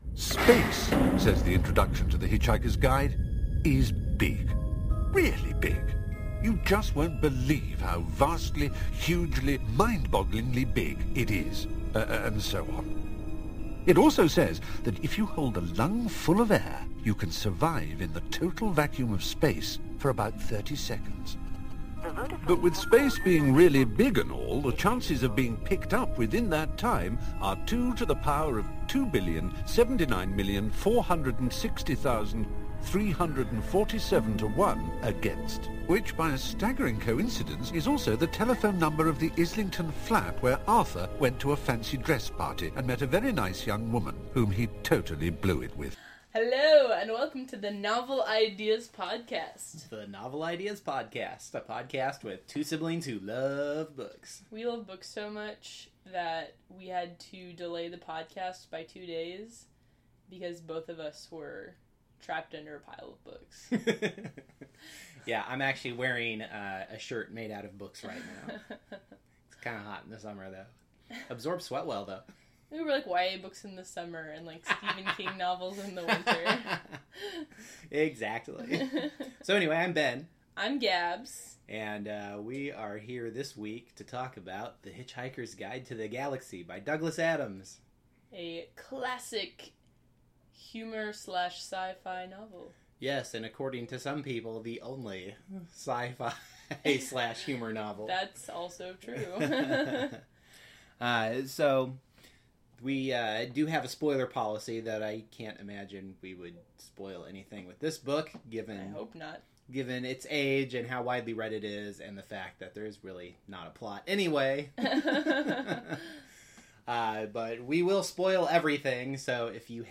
No music bump, but the opening clip is from the 2005 big budget movie production and the ending clip is from the 1981 BBC production.